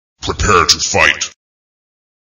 Quake 3 sounds
prepare-to-fight.ogg